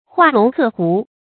畫龍刻鵠 注音： ㄏㄨㄚˋ ㄌㄨㄙˊ ㄎㄜˋ ㄏㄨˊ 讀音讀法： 意思解釋： 比喻好高鶩遠，終無成就。